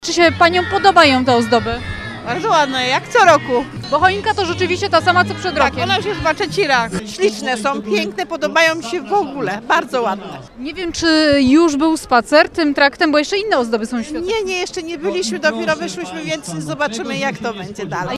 Dzieci, rodzice i dziadkowie byli zachwyceni. Tuż przy choince grał kataryniarz.